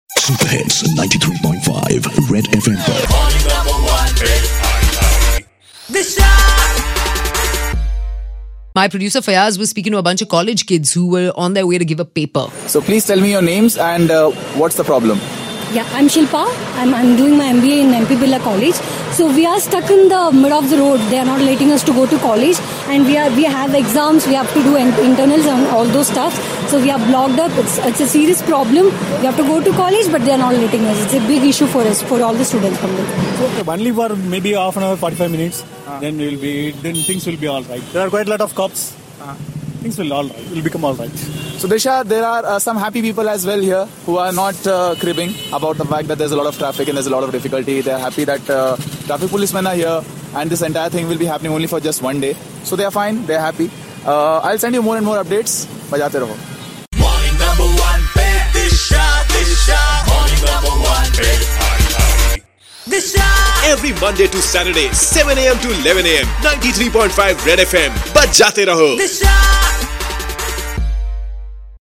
Live OB from Raj Bhavan Road